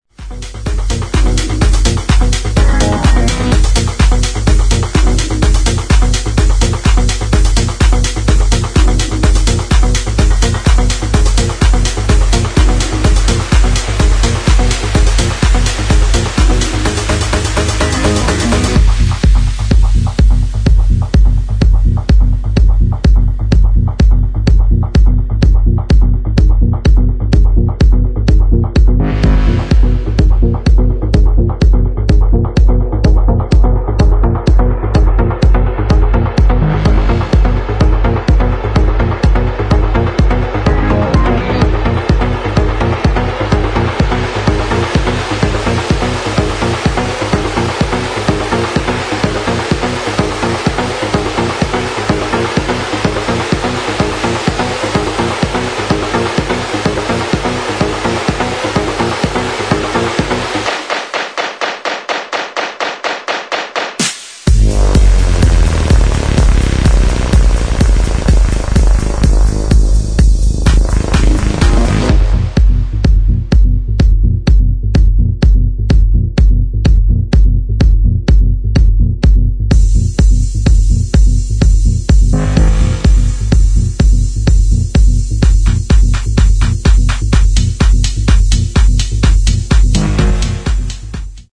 [ TECHNO ]